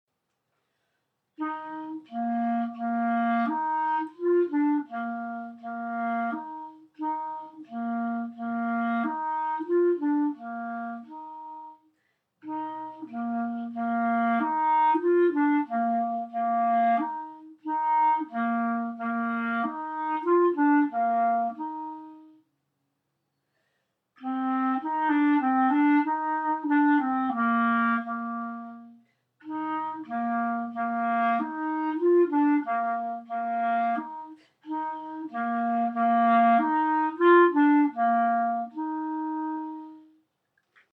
Prelude Clarinet Only